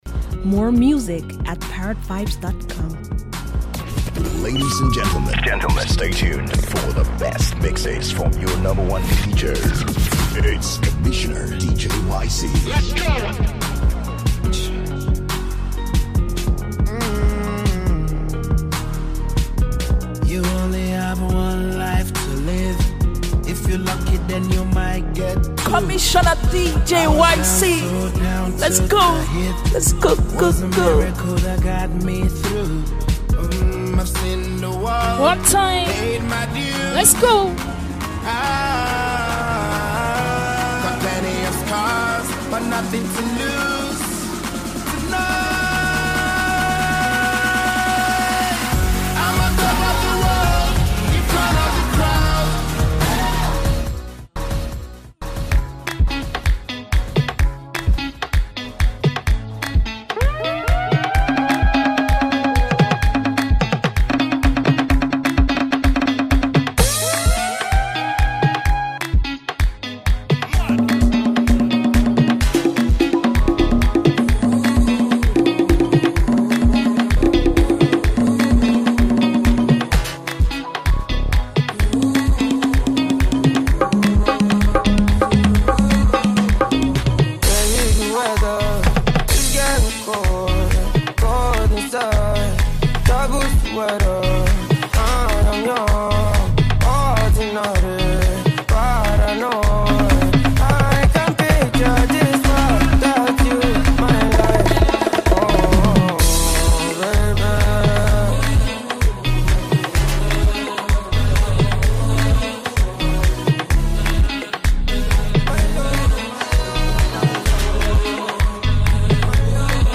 Trailblazing Nigerian disc jockey and music producer